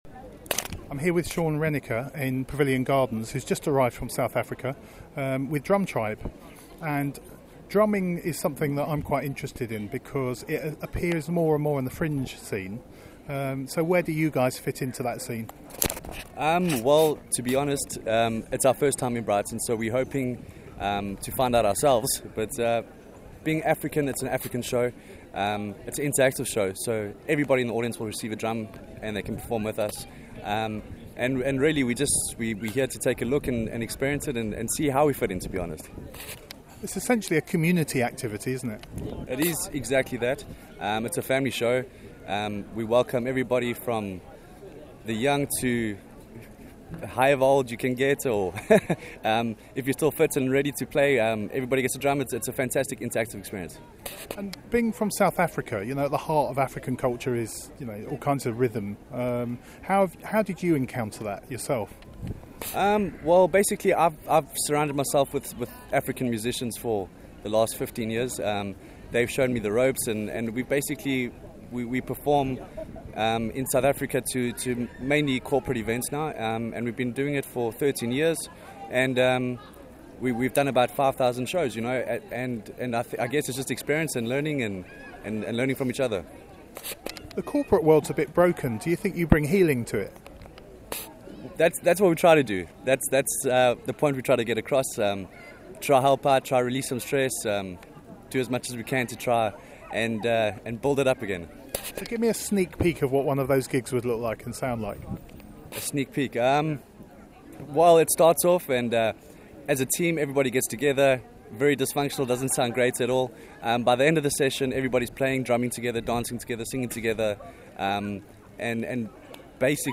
Drum Tribe, from South Africa, bring the sounds of Africa to the Fringe. We found out more in this interview with them on the day of their arrival in Brighton.
listen-to-our-interview-with-drum-tribe.mp3